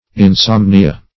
Insomnia \In*som"ni*a\, n. [L., fr. insomnis sleepless; pref.